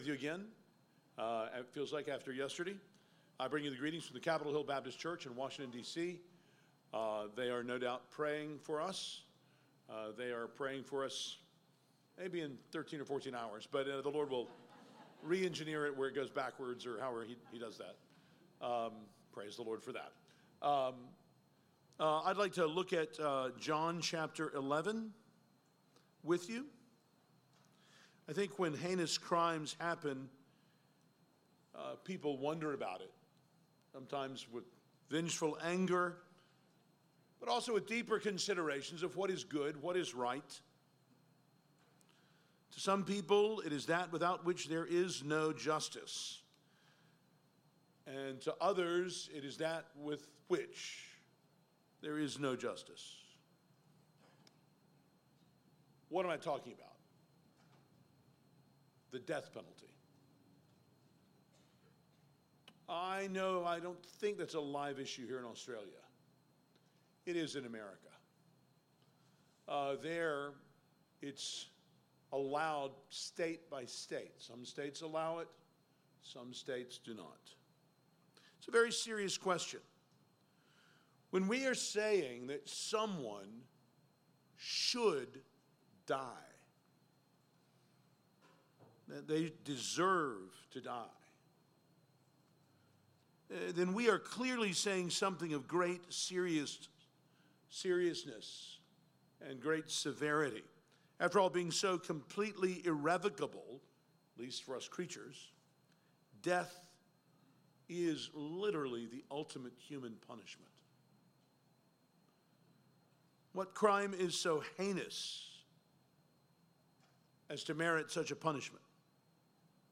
Jesus' enemies said that Jesus needed to die for the nation to be saved. They were both right and wrong. This sermon